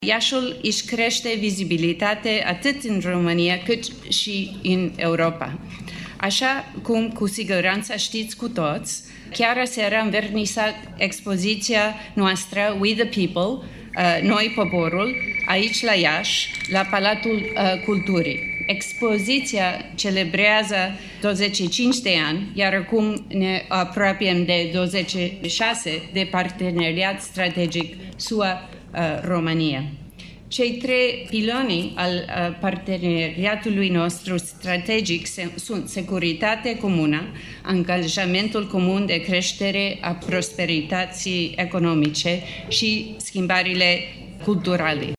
Cea mai mare clădire de birouri din România, Palas Campus, a fost inaugurată astăzi, la Iaşi, în prezenţa premierului Nicolae Ciucă şi a ambasadorului SUA la Bucureşti, Kathleen Kavalec.